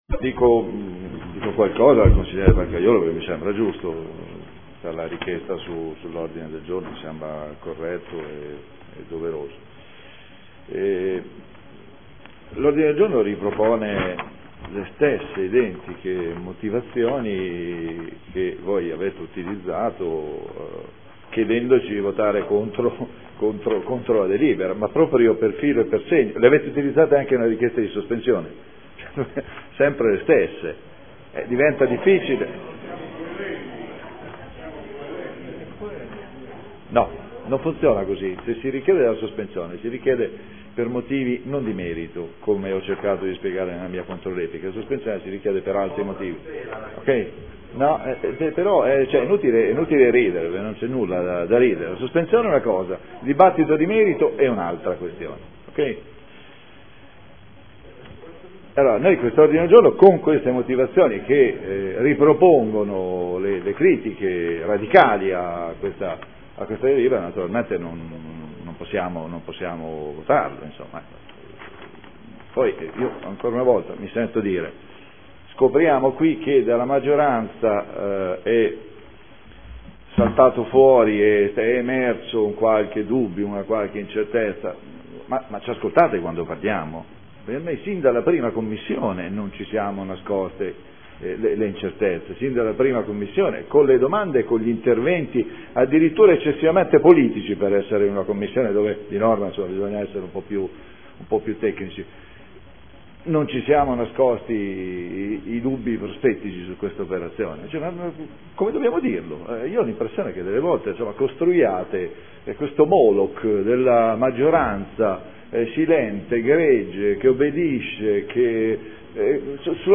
Seduta del 23/01/2014 Dichiarazione di Voto. Adesione del Comune di Bologna a Emilia Romagna Teatro Fondazione in qualità di socio fondatore necessario.